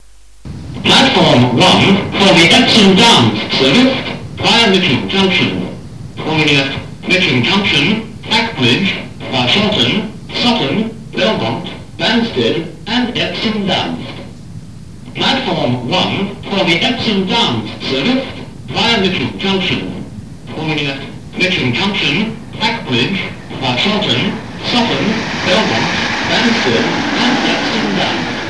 Balham pre-recorded platform announcement number 136 (year:1989)